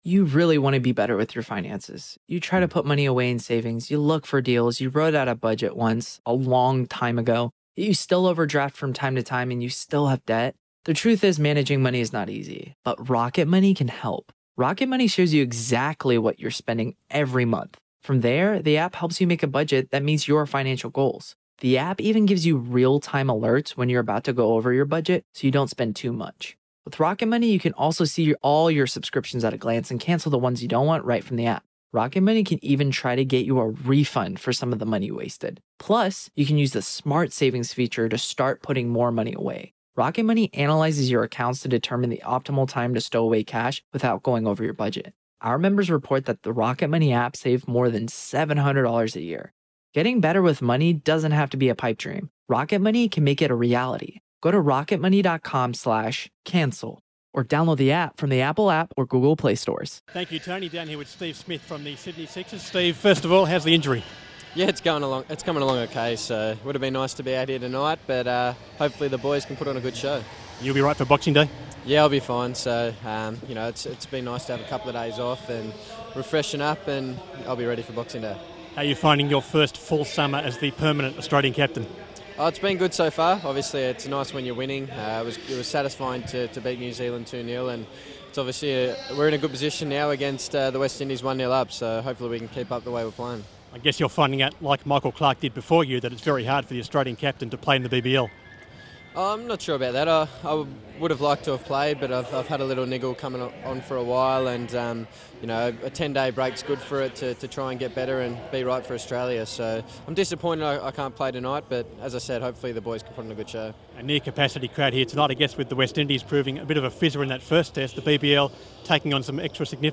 Steve Smith full pre-game interview